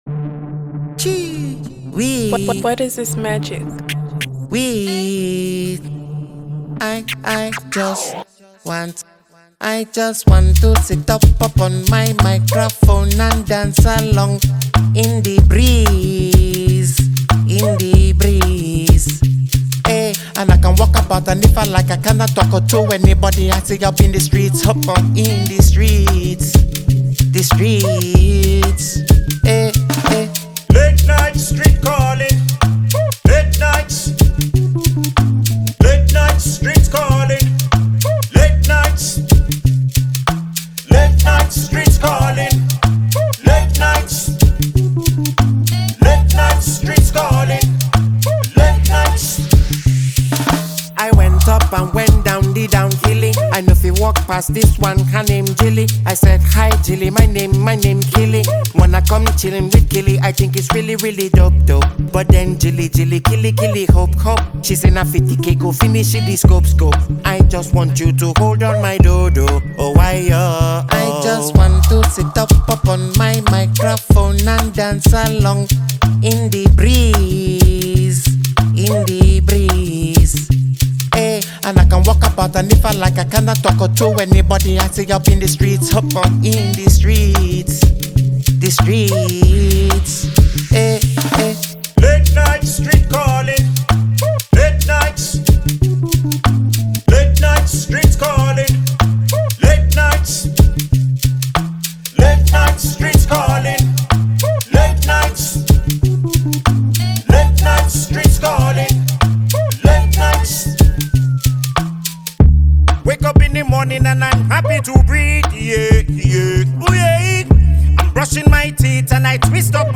a reggae-type of vibes with limited instrumental